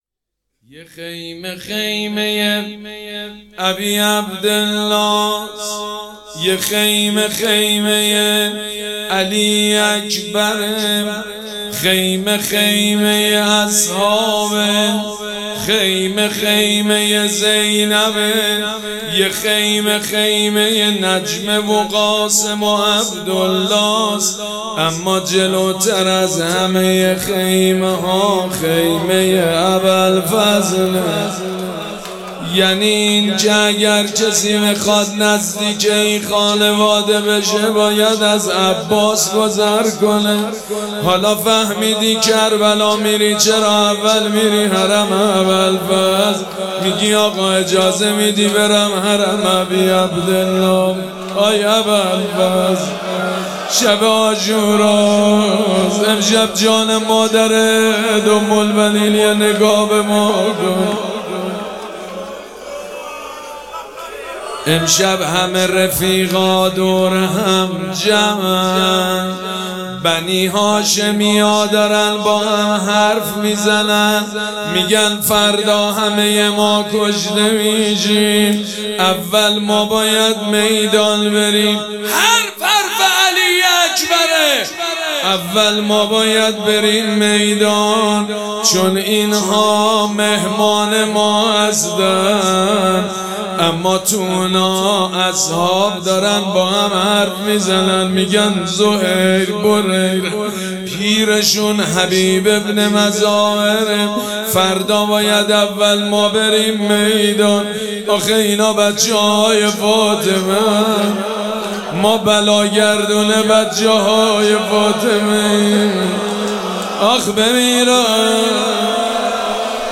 مراسم عزاداری شب دهم محرم الحرام ۱۴۴۷
روضه